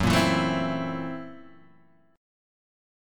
F#7#9b5 chord {2 1 2 2 1 0} chord